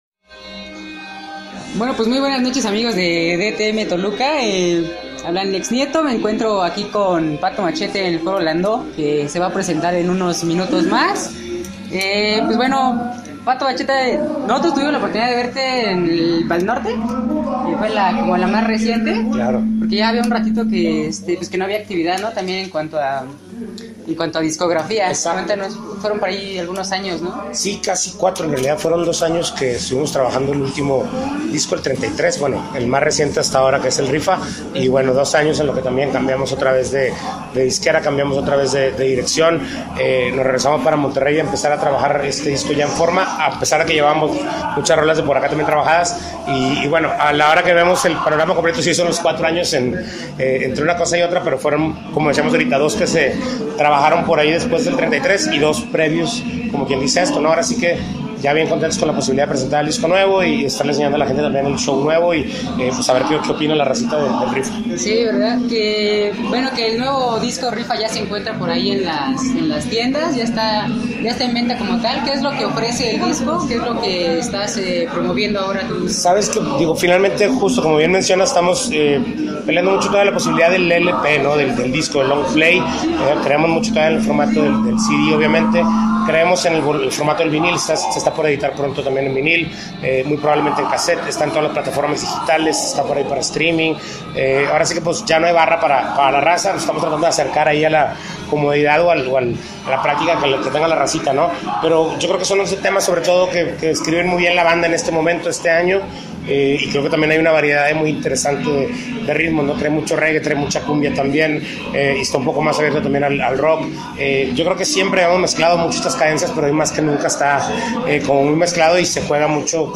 Entrevista con Pato Machete